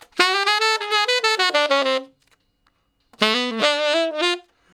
066 Ten Sax Straight (D) 14.wav